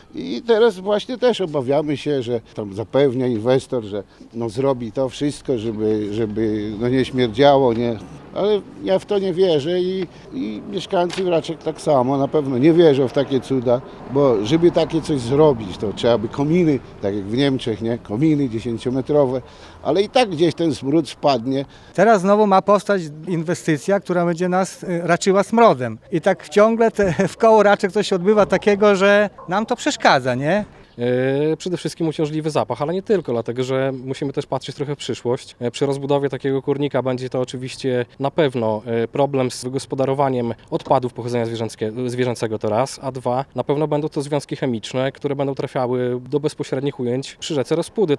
Swój problem przedstawili w piątek (12.05) w Gminnym Ośrodku Kultury w Raczkach.
mieszkańcy-1.mp3